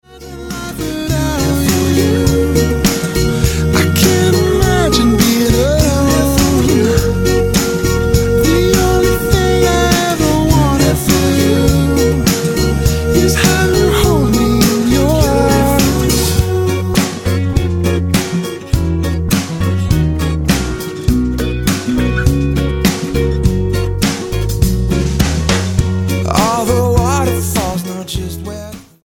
Roots/Acoustic
Style: Pop